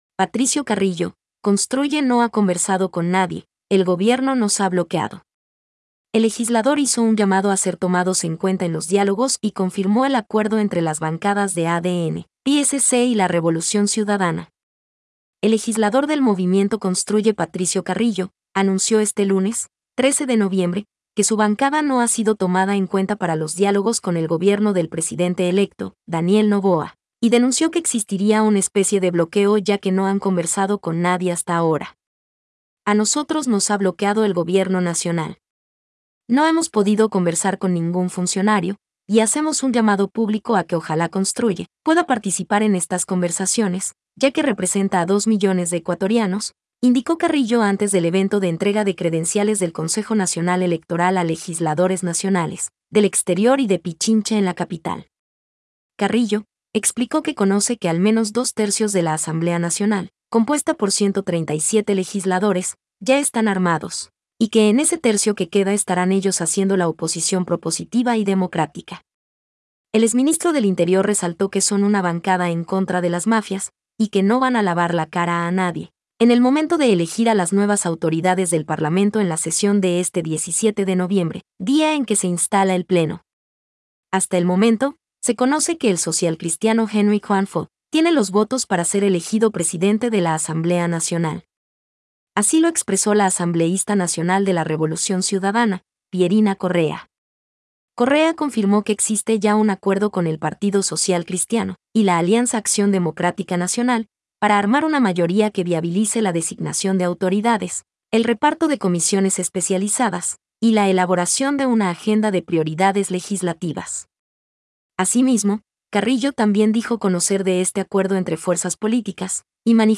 Noticia hablada…